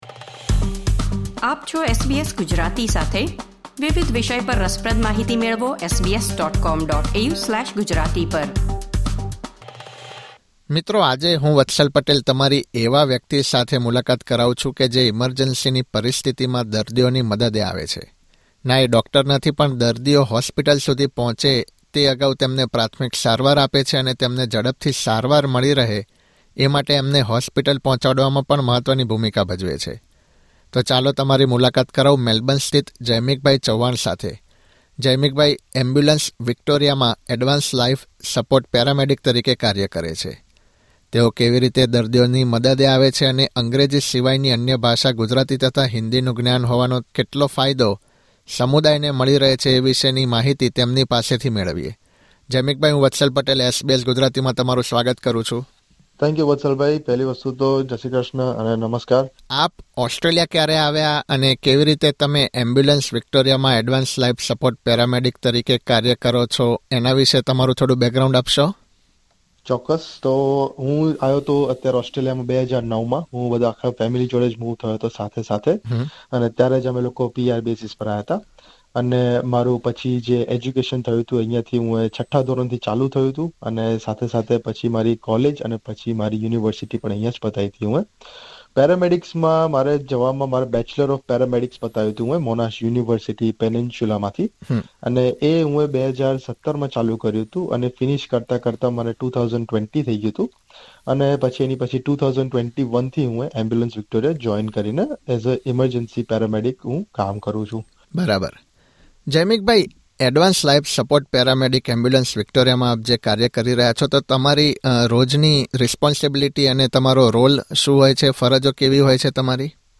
SBS Gujarati